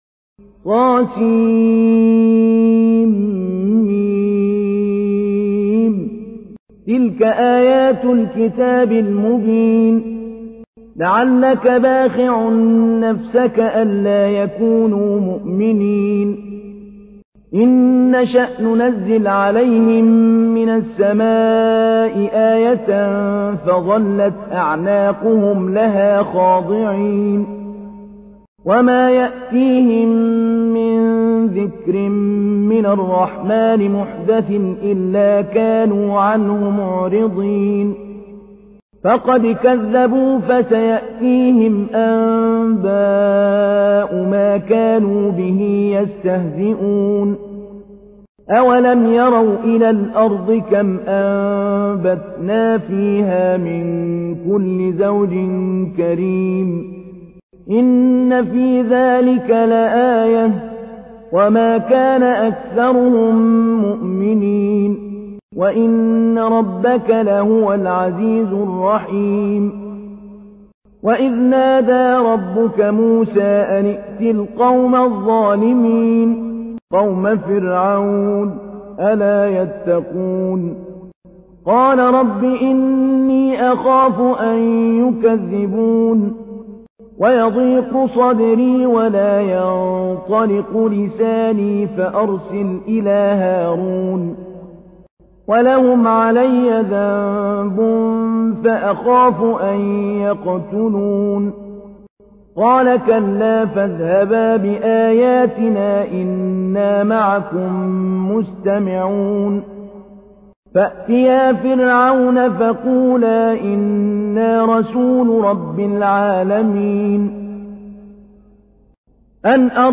تحميل : 26. سورة الشعراء / القارئ محمود علي البنا / القرآن الكريم / موقع يا حسين